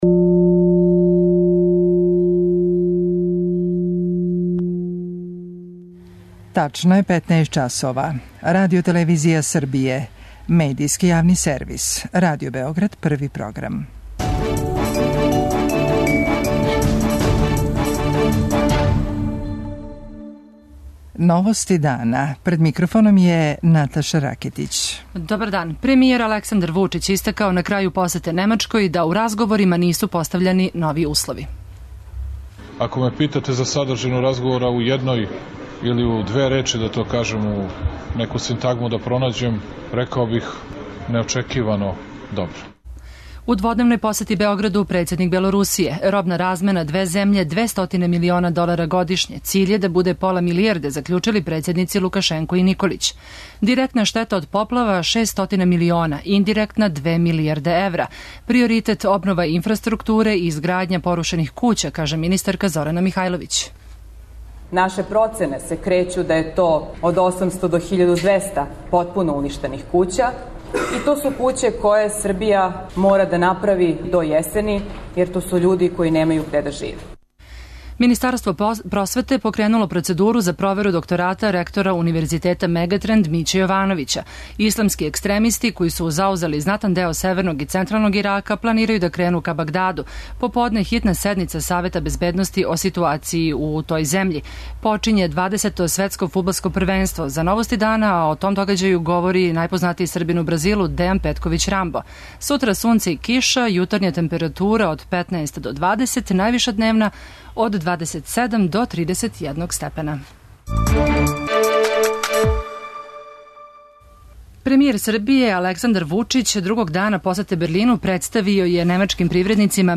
О светском фудбалском првенству, за наш радио, говори најпознатији Србин у Бразилу, Дејан Петковић Рамбо.